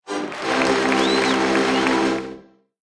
delighted_06.ogg